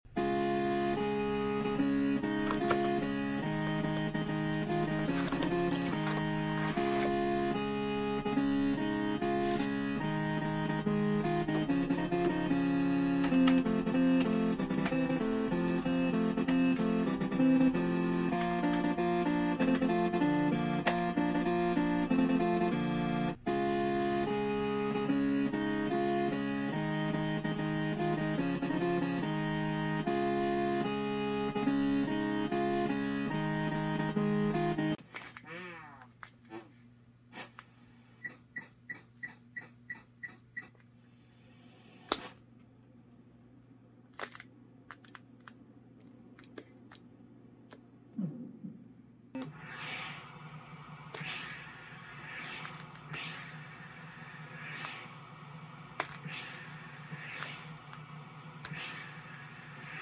Winter Games C64 sound effects free download